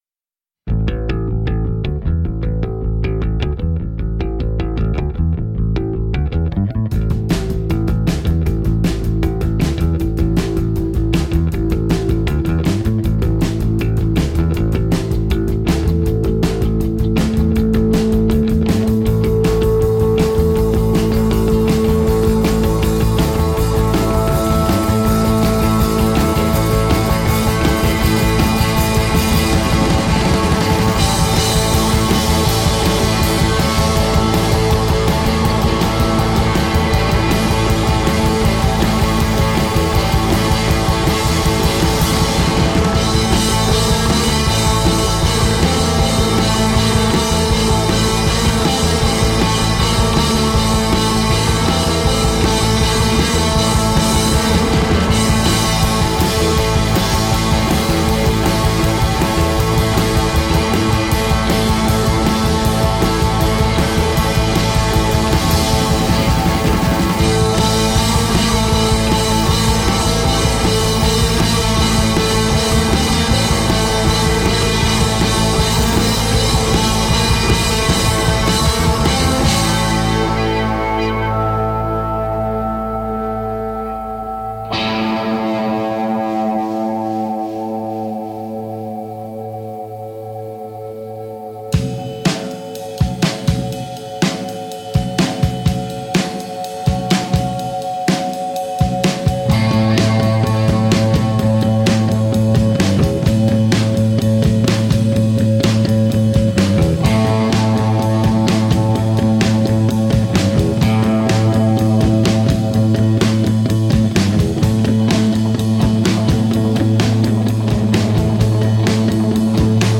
five-piece band from Zaragoza, Spain